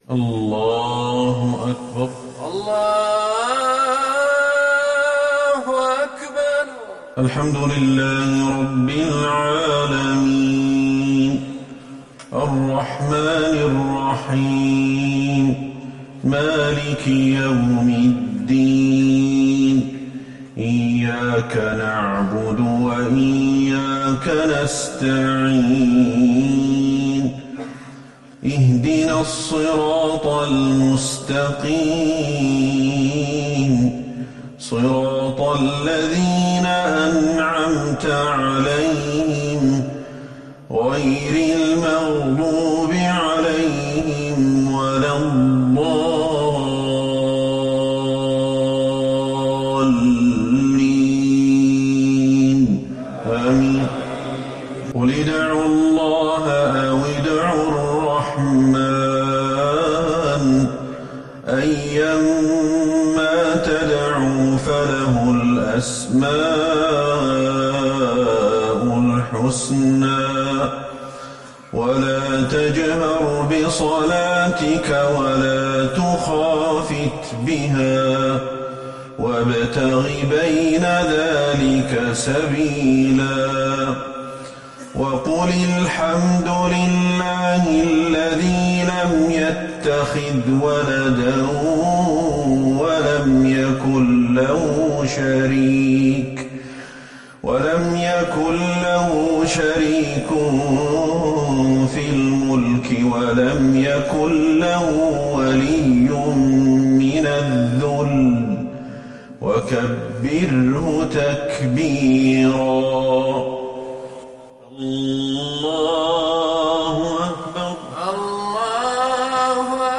صلاة المغرب للقارئ أحمد الحذيفي 28 جمادي الأول 1441 هـ
تِلَاوَات الْحَرَمَيْن .